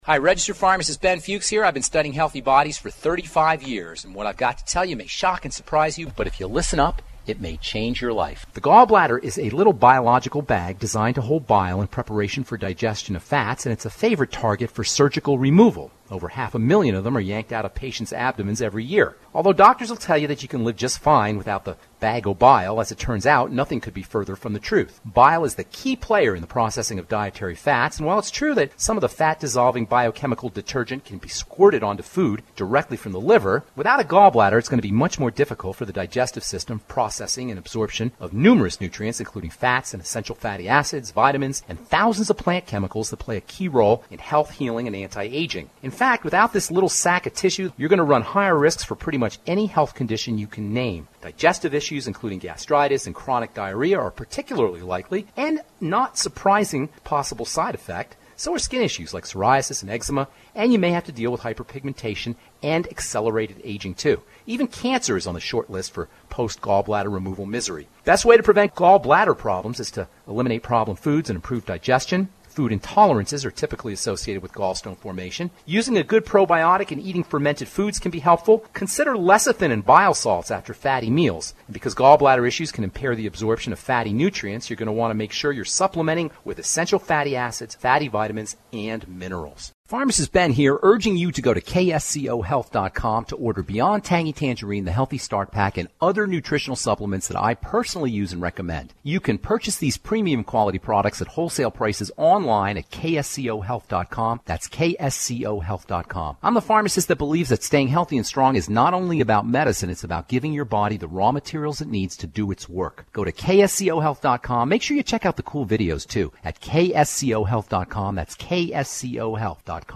Two-minute daily health updates radio stations can drop directly into commercial breaks.